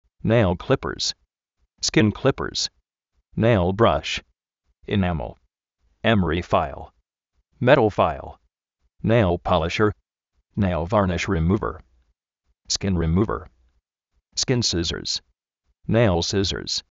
néil klípers
skín klípers
néil brash
enámel
émeri fáil
néil várnish remúver